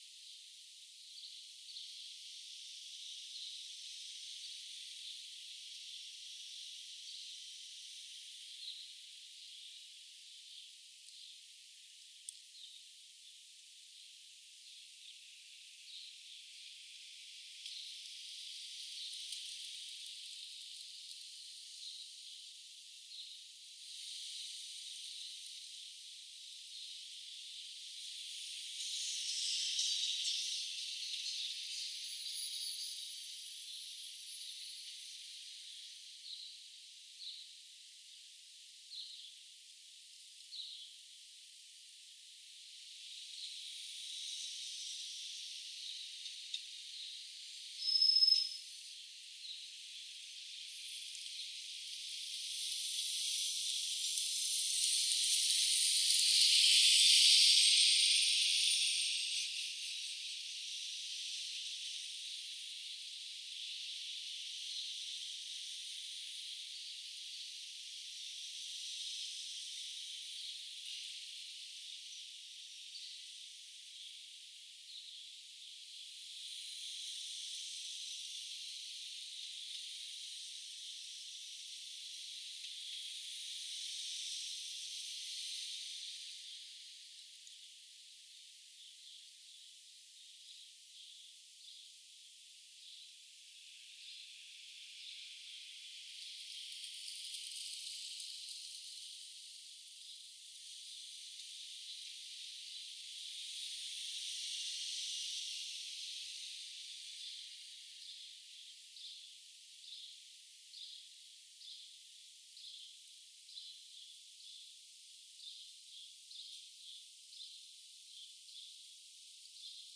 Demonstration soundscapes
497447 | biophony 497439 | biophony 497440 | anthropophony 497446 | rain